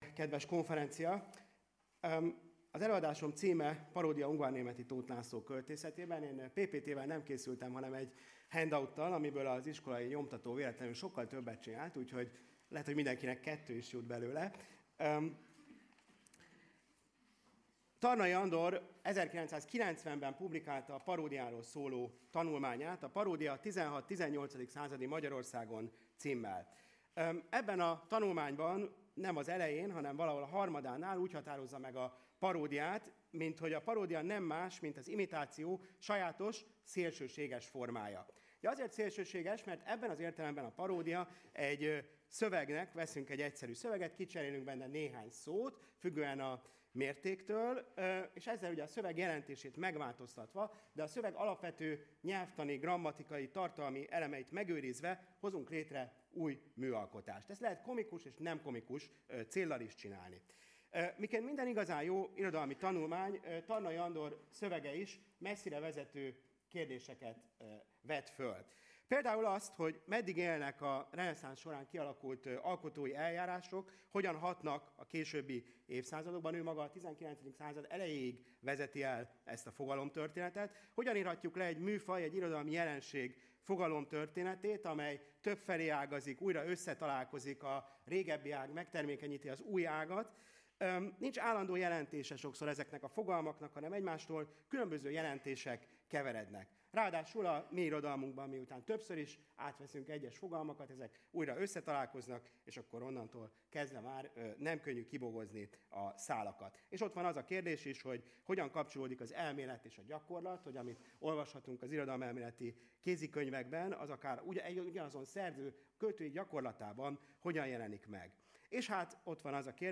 Hagyományőrzés és önbecsülés. Száz éve született Tarnai Andor , Kilencedik ülés